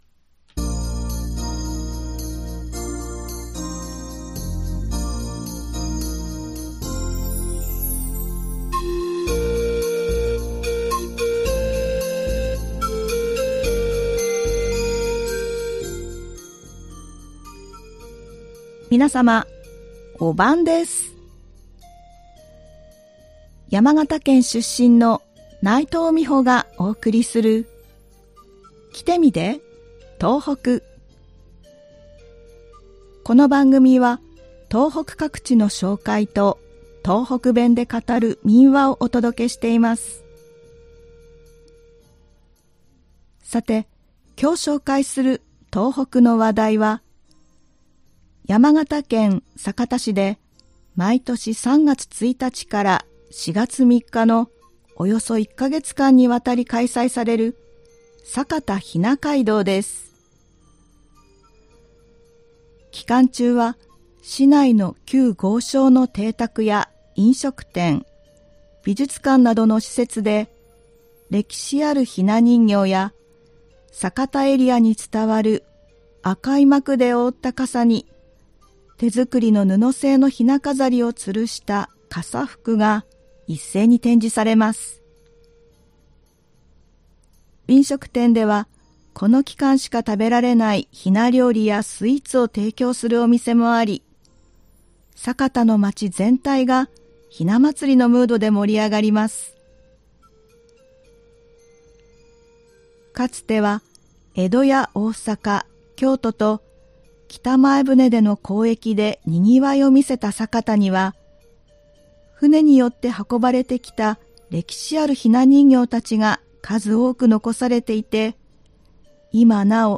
この番組は東北各地の紹介と、東北弁で語る民話をお届けしています。
今回は3月1日から4月3日に山形県酒田市で開催される「酒田雛街道」を紹介しました。 ではここから、東北弁で語る民話をお送りします。今回は山形県で語られていた民話「三枚のお札」です。